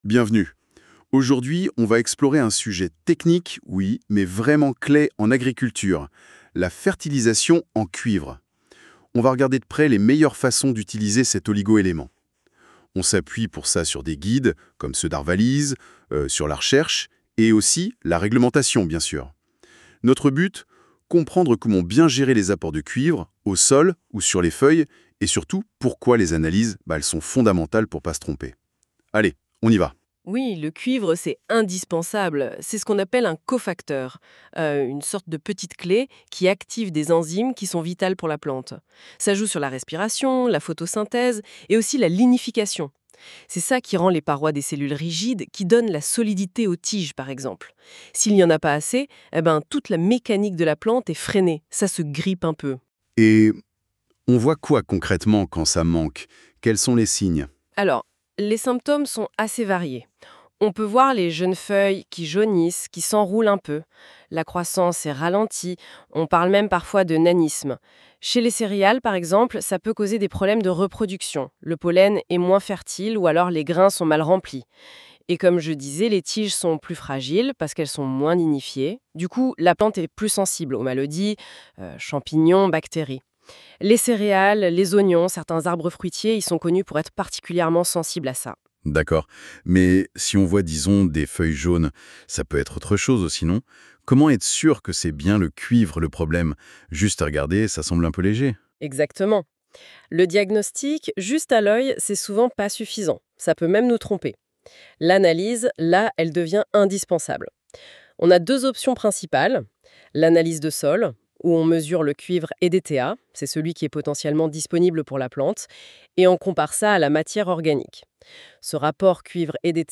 (créé par IA)